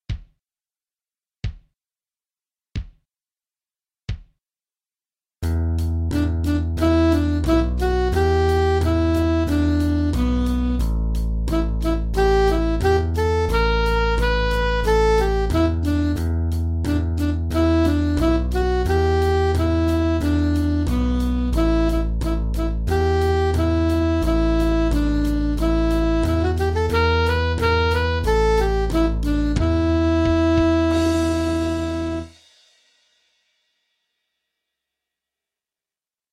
"Chord Change Study" blues accompaniment track
0302EmA7BluesTrack.mp3